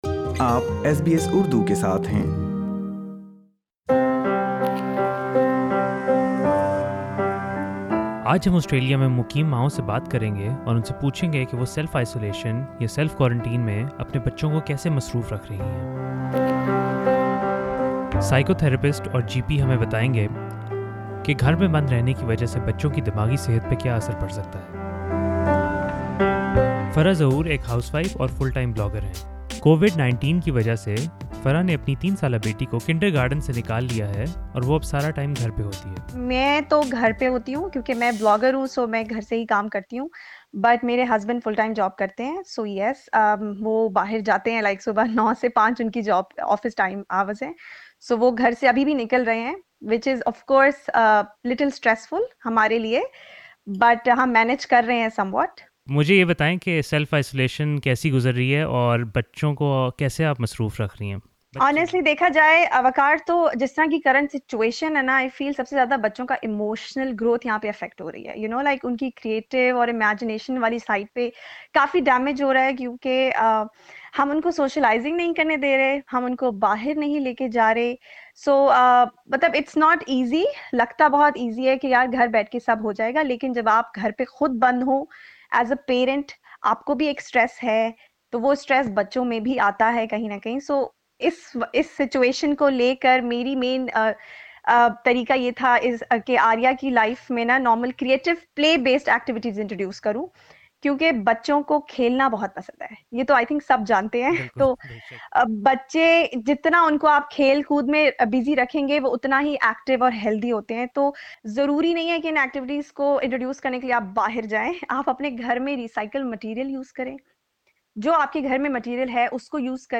ایس بی ایس اردو نے آسٹریلیا میں ماؤں سے پوچھا کہ سیلف آئیسولیشن کے دوران وہ اپنے بچوں کو کیسے مصروف رکھ رہی ہیں۔ گھر پر بند رہنے سے بچوں کی دماغی صحت پر کیا اثر ہوتا ہے اس سلسلے میں سنیئے ایک ماہرِ نفسیات اور ڈاکٹر سے گفتگو۔